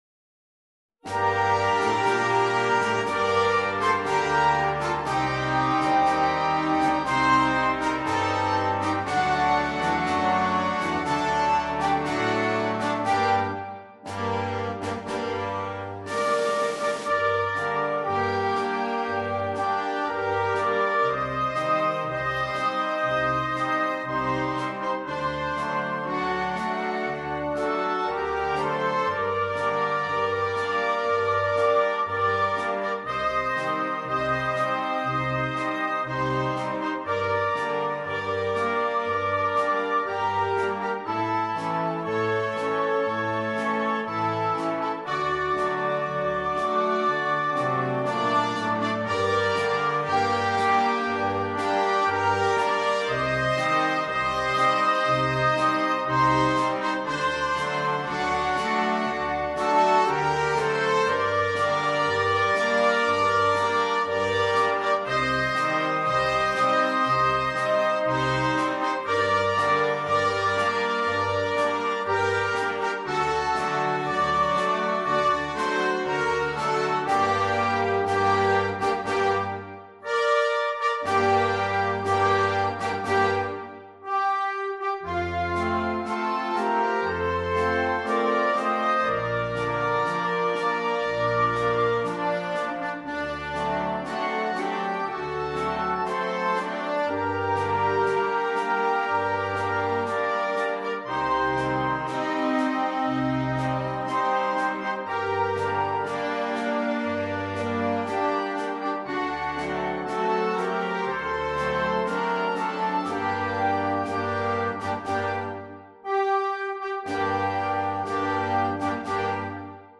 MUSICA PER BANDA
Marcia funebre